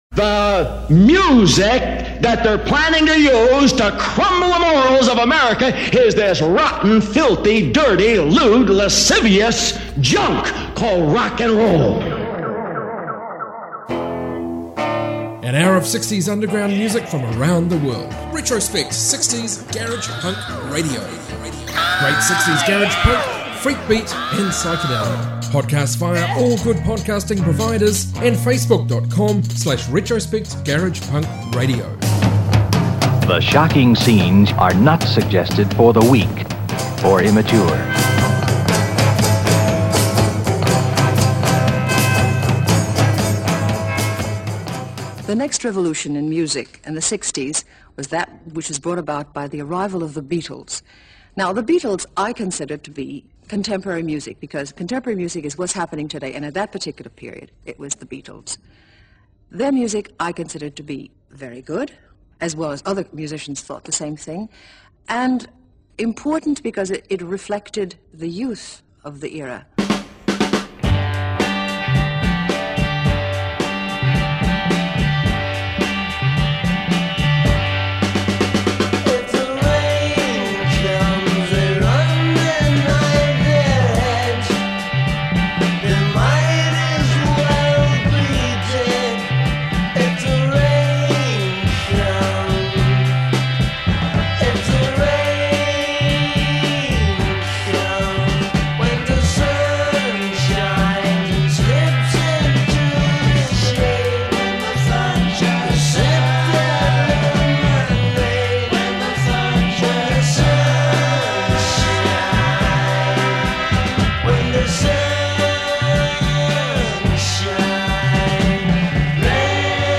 60s garage